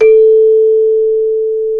CELESTE 2 A3.wav